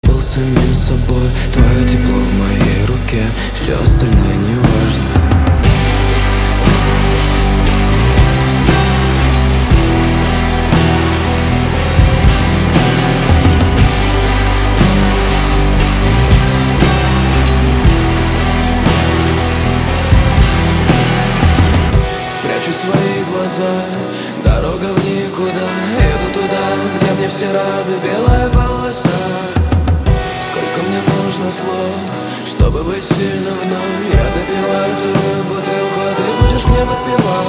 • Качество: 128, Stereo
атмосферные
Alternative Rock